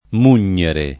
mugnere [ m 2 n’n’ere ]